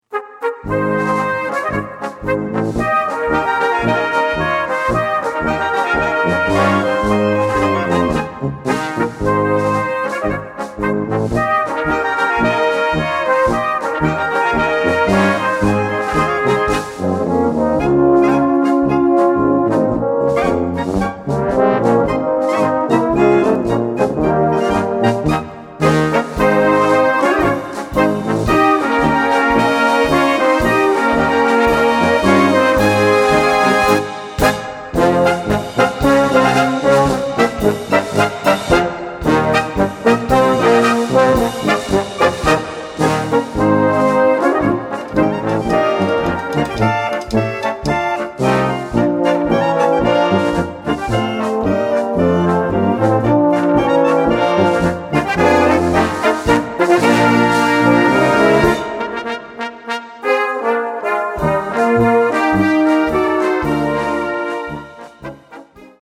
Gattung: Böhmische Polka für Blasorchester
Besetzung: Blasorchester